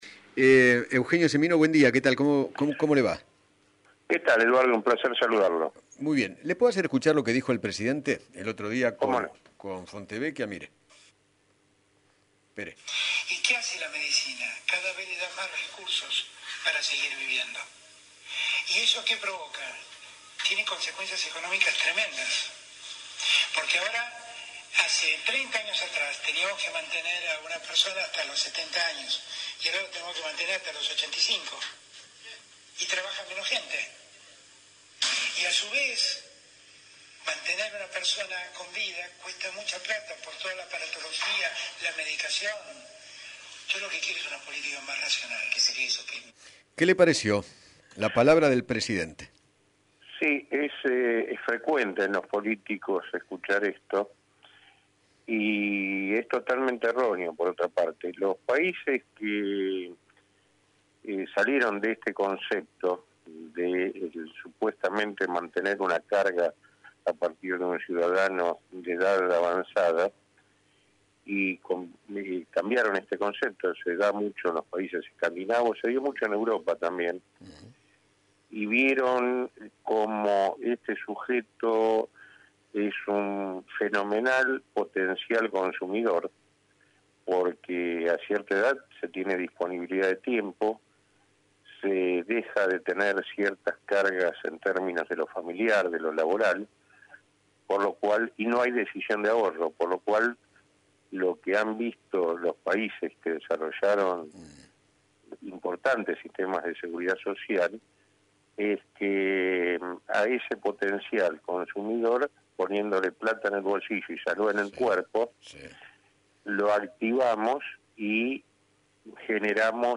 Eugenio Semino, Defensor de la Tercera Edad, dialogó con Eduardo Feinmann sobre lo sucedido en un geriátrico de Belgrano, donde se detectaron que siete personas fueron contagiadas por coronavirus.